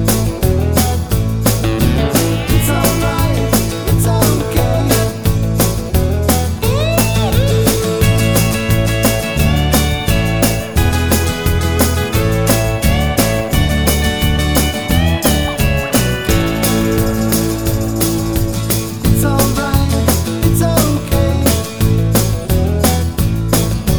no Backing Vocals T.V. Themes 2:16 Buy £1.50